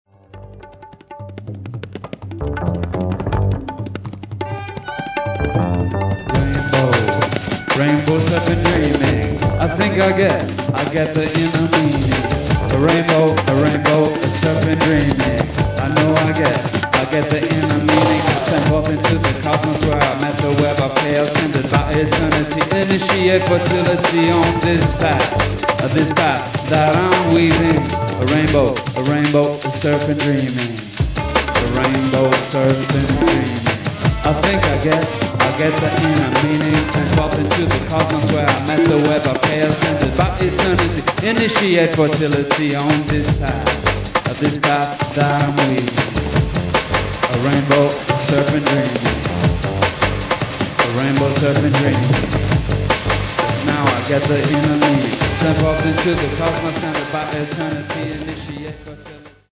Fully improvised drum n' bass/spoken word exploration
(vocals, alto saxophone, double bass, samples, tabla)